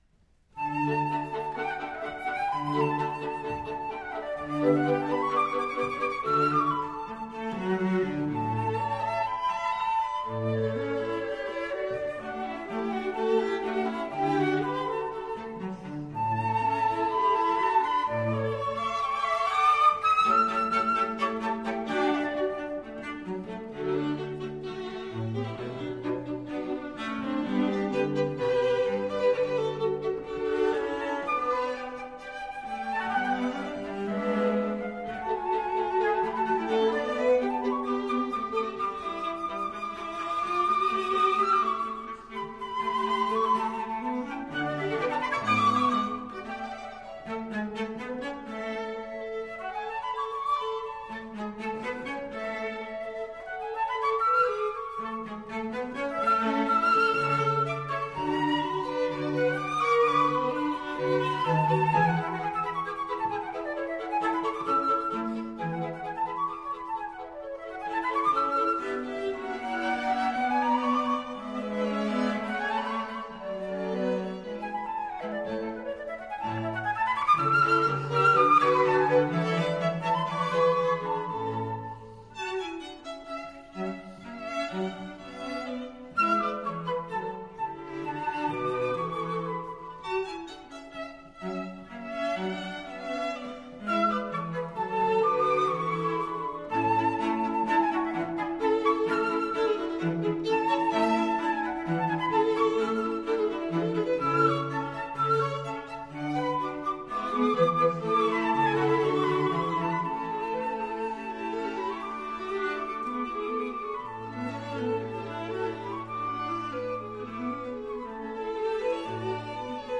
Flute and Ensemble
Violin Viola Cello
Style: Classical
Musicians from Marlboro (ensemble) Paula Robison (flute)